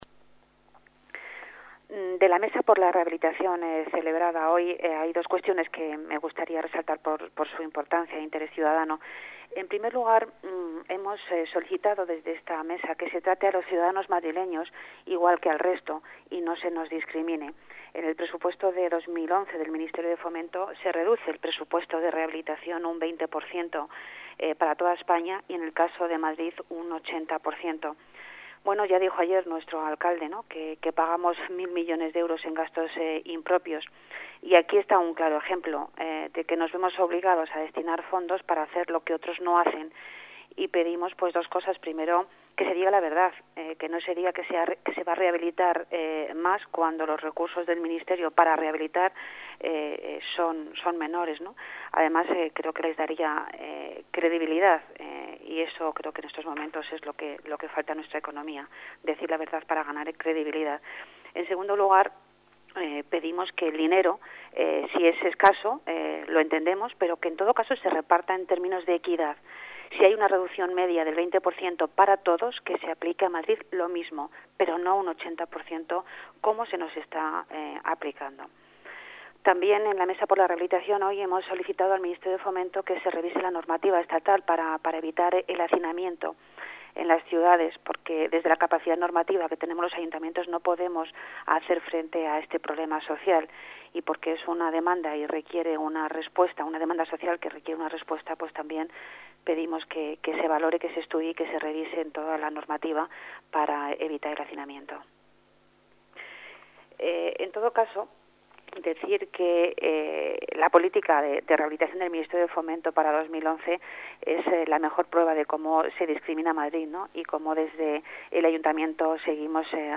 Nueva ventana:Declaraciones de Pilar Martinez en la Mesa por la Rehabilitación